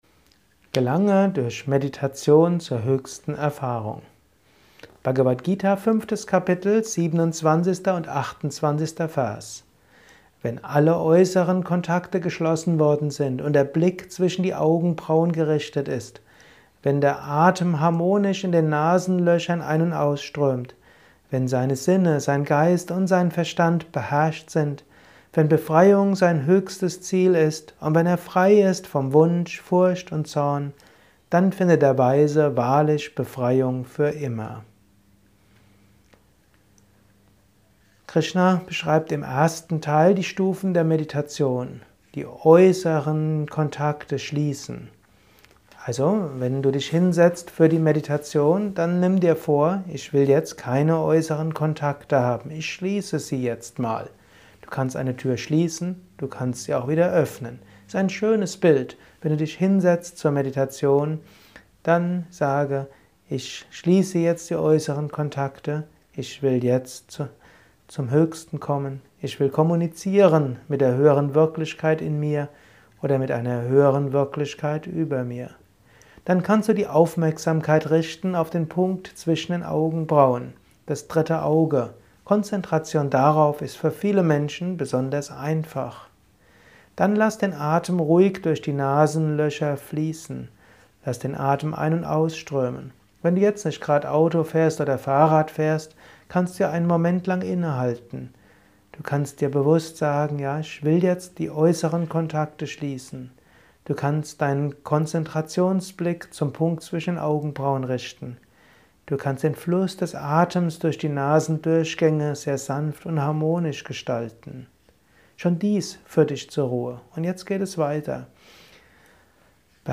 Dies ist ein kurzer Kommentar